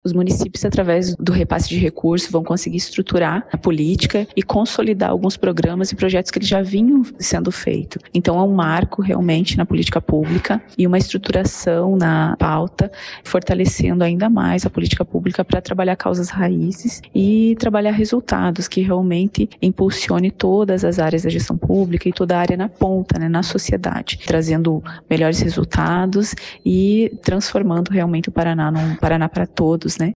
Sonora da diretora de Igualdade Racial, Povos e Comunidades Tradicionais da Semipi, Ivânia Ramos, sobre o repasse de recursos aos municípios para políticas antirracistas